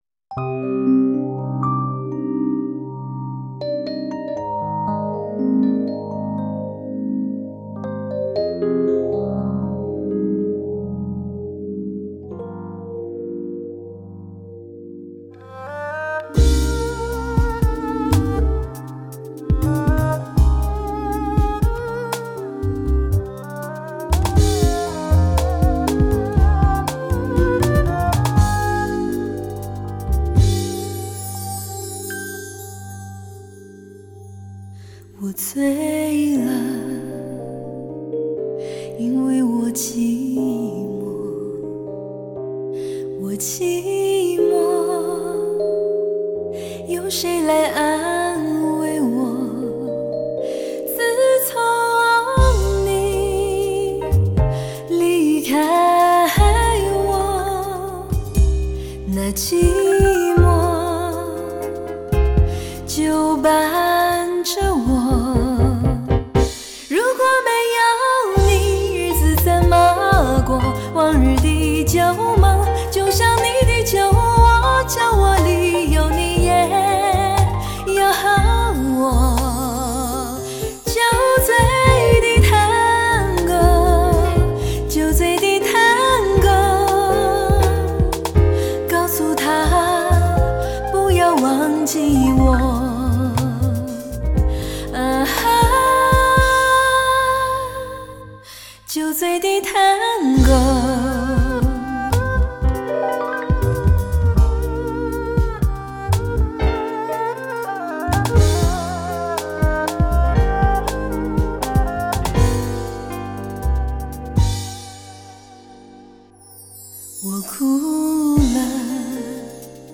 多首歌以纯吉他为主配器